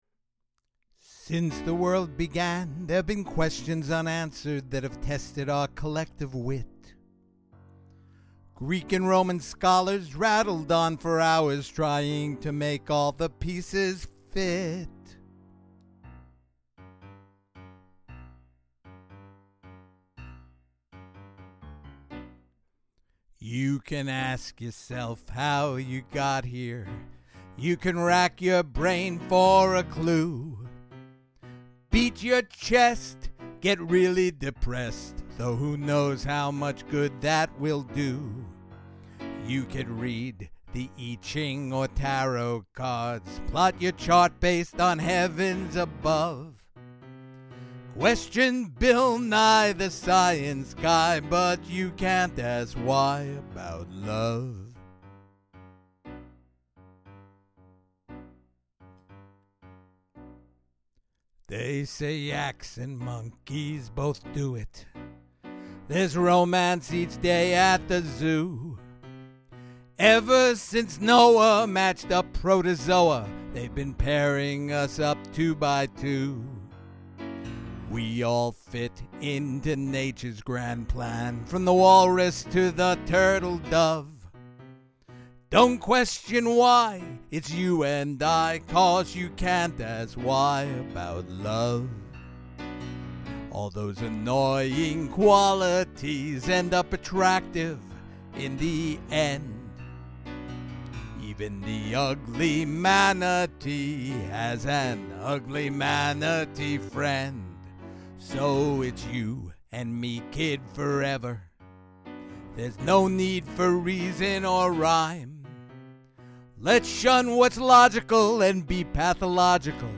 It's an AABA song that's quirky and fun.